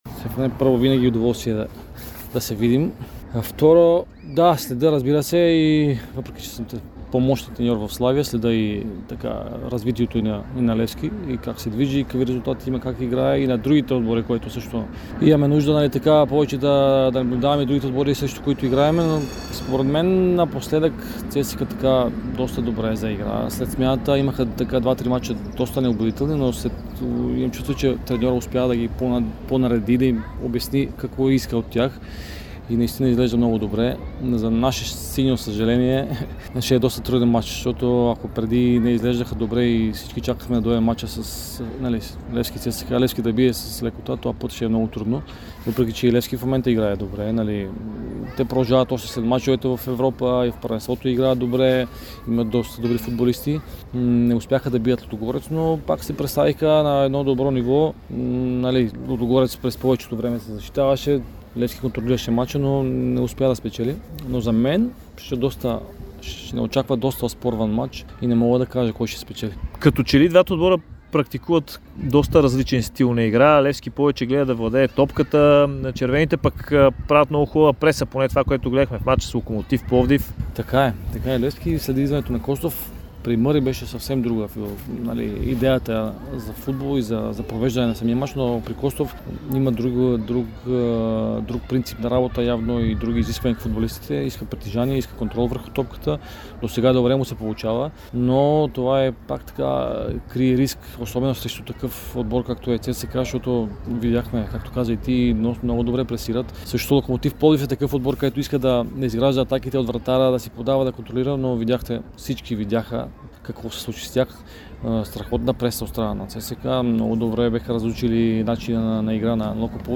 Поводът бе първа копка на ново футболно игрище в едноименния квартал.
Тасевски се съгласи да говори за утрешното дерби.